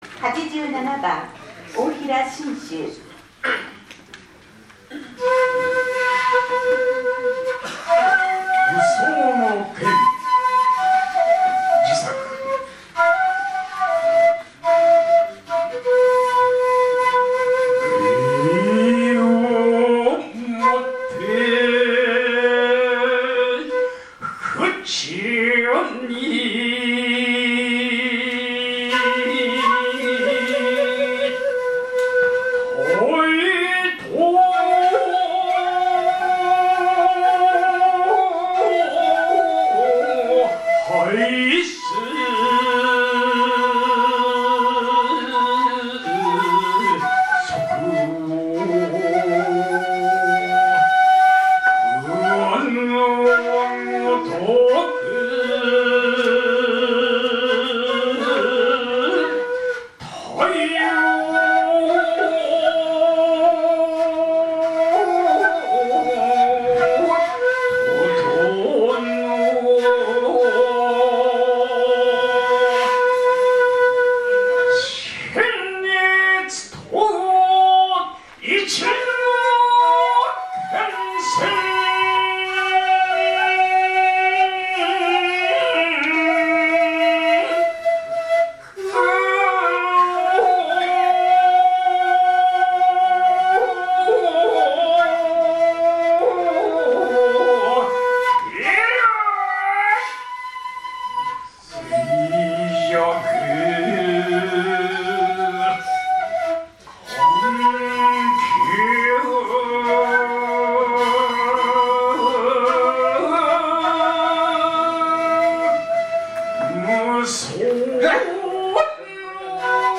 詩吟神風流 第９１回全国大会特集
平成２９年１１月１８日（土） 於 上野精養軒
会長・支部長吟詠